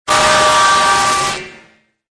Descarga de Sonidos mp3 Gratis: distorsion 11.